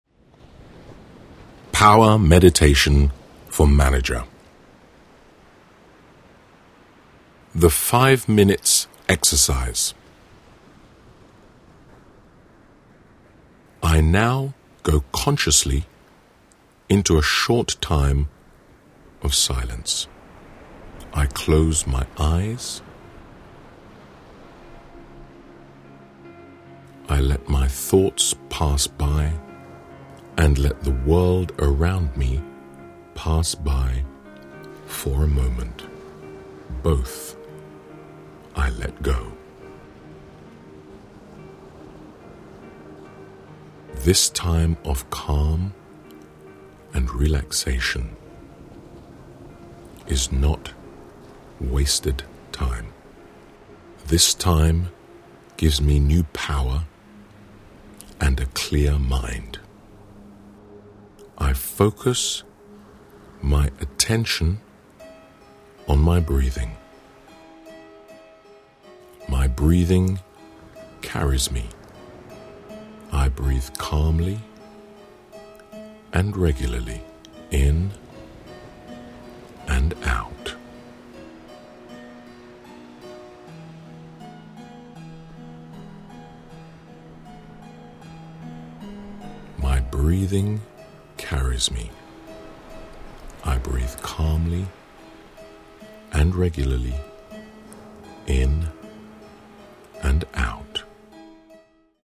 Modules in this audiobook:
The "Power Meditation for Managers" is set to stimulating (neo-)classical music, which particularly induces brain activity, such as joined-up thinking and intuition, and generally animates mental vigor.